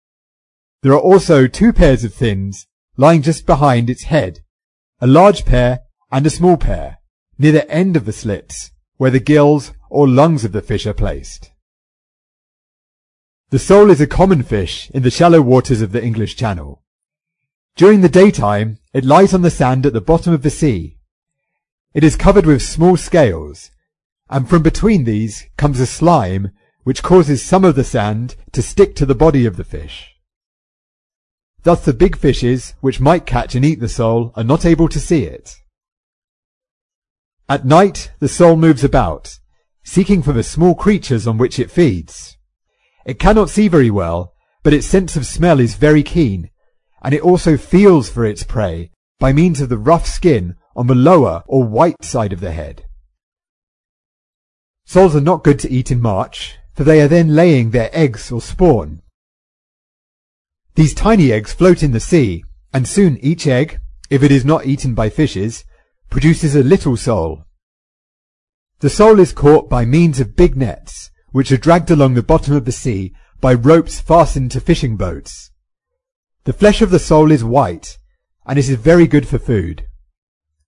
在线英语听力室提供配套英文朗读与双语字幕，帮助读者全面提升英语阅读水平。